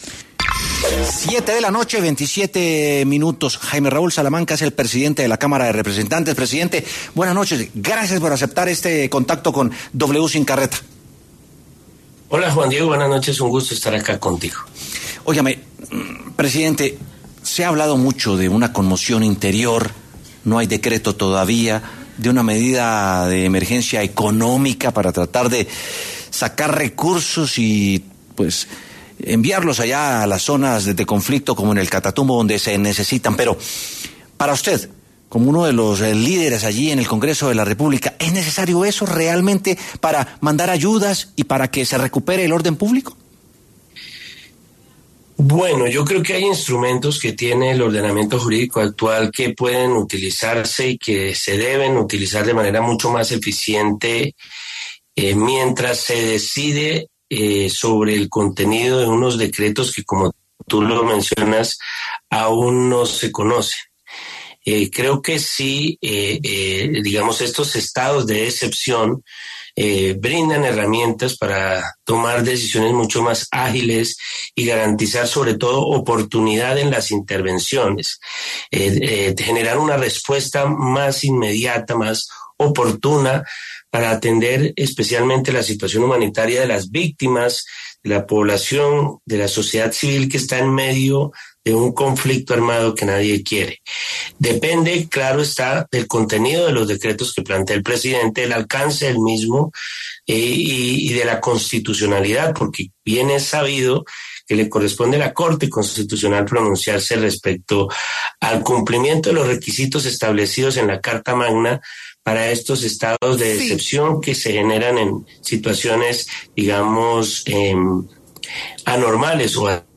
Jaime Raúl Salamanca, presidente de la Cámara de Representantes, conversó en W Sin Carreta sobre la declaración de estado de conmoción por parte del Gobierno Petro y el papel que jugará el Congreso en ella.
Para hablar sobre el tema, pasó por los micrófonos de W Sin Carreta el presidente de la Cámara de Representantes, Jaime Raúl Salamanca, explicando el papel del Congreso en este periodo de conmoción.